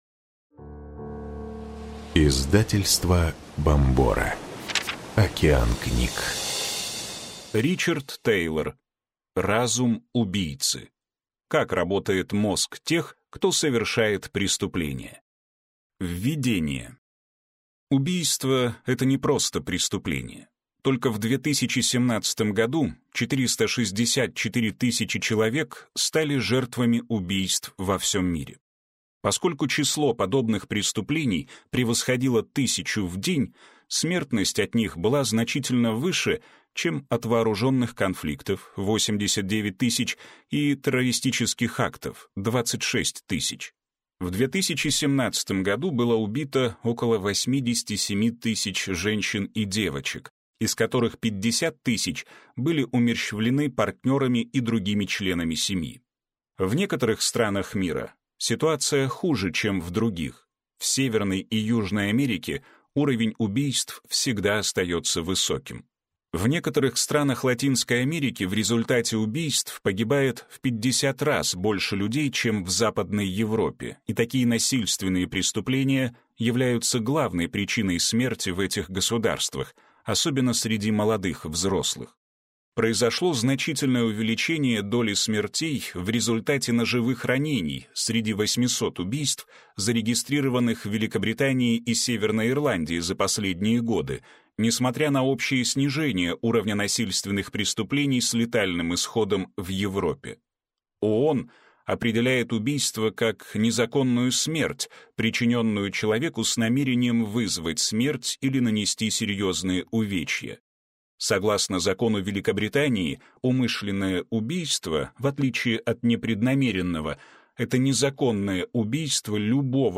Аудиокнига Разум убийцы. Как работает мозг тех, кто совершает преступления | Библиотека аудиокниг
Прослушать и бесплатно скачать фрагмент аудиокниги